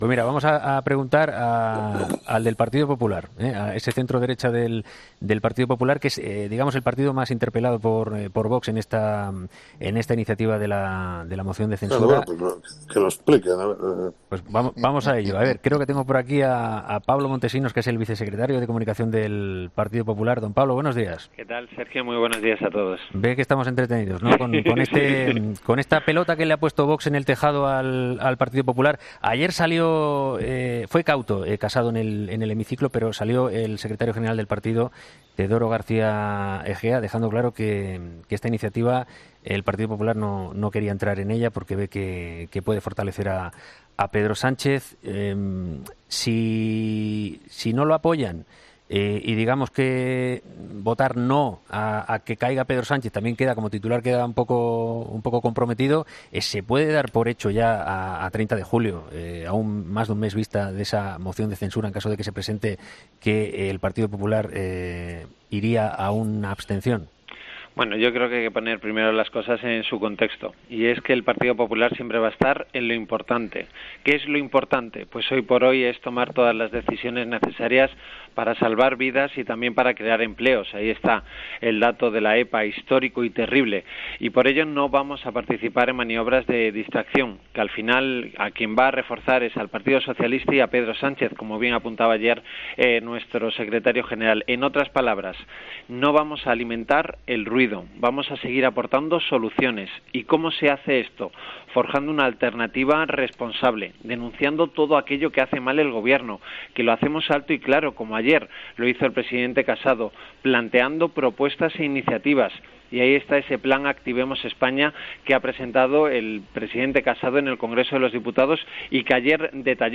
Pablo Montesinos, vicesecretario de comunicación del PP, ha sido entrevistado este jueves en 'Herrera en COPE' después de la moción de censura contra Pedro Sánchez anunciada por Vox .